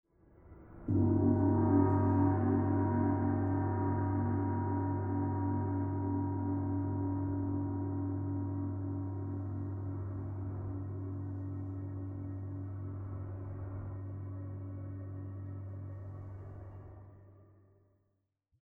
To support that, I created an echoing ambience from stretched samples of gongs and wind, layered together to evoke the large, high-ceilinged spaces common to houses of worship. An unmodified sample of a gong was used to cue to actors to “wake” from their trance and begin Act I.
pre-show.mp3